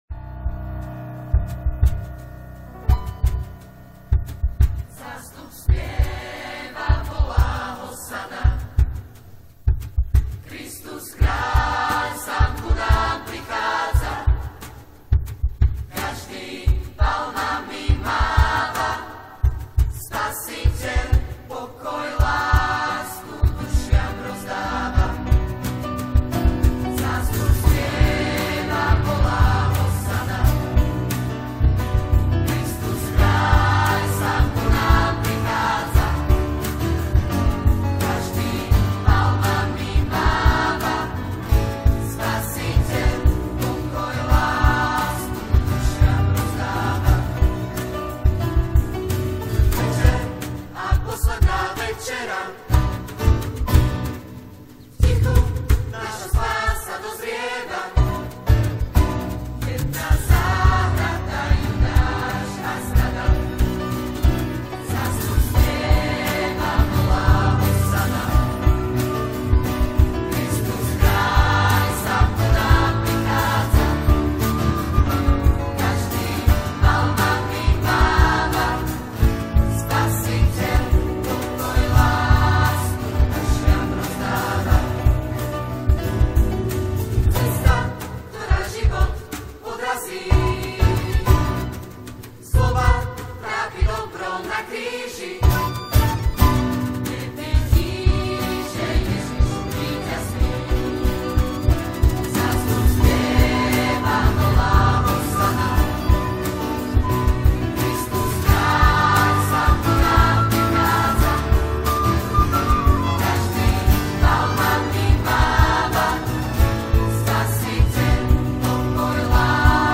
spirituál